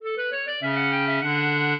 minuet13-12.wav